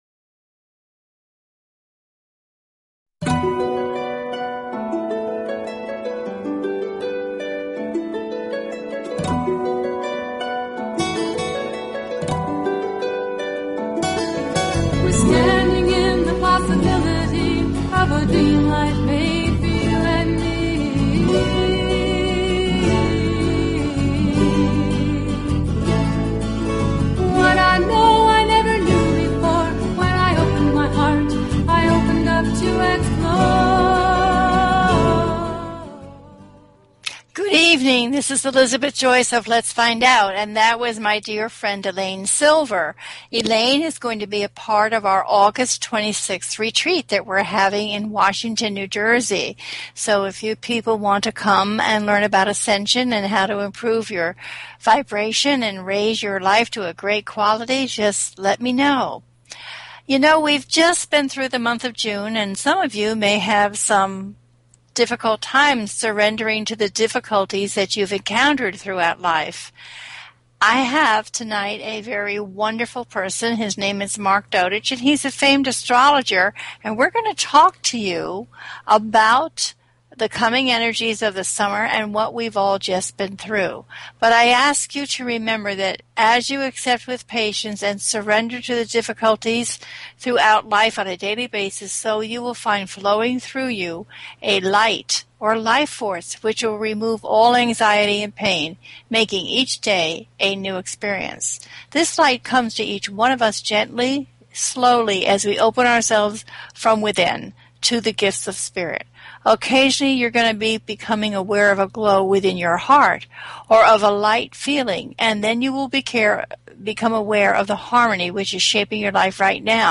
Talk Show Episode
THIS IS A CALL IN SHOW, so please get your questions ready and give us a call.